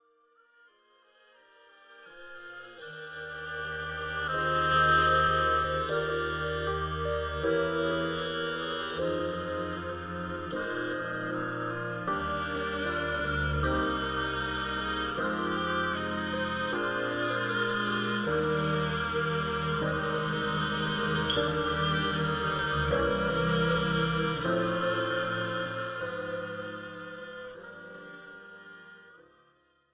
This is an instrumental backing track cover.
• Without Backing Vocals
• No Fade